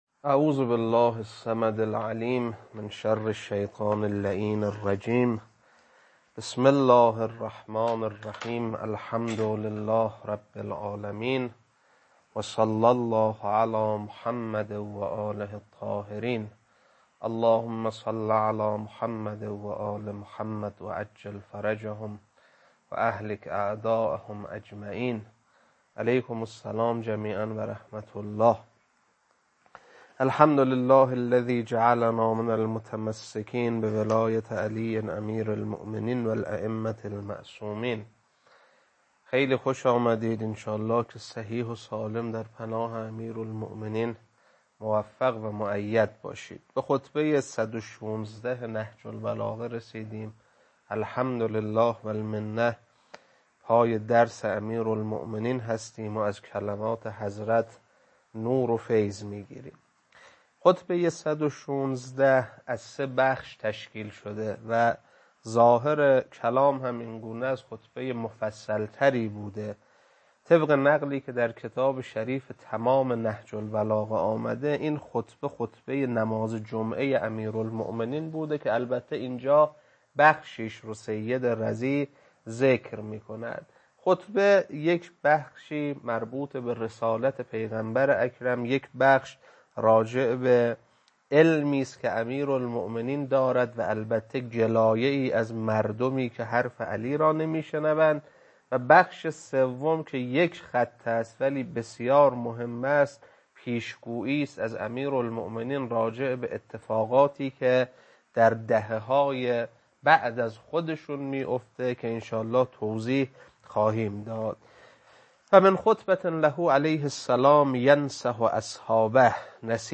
خطبه 116.mp3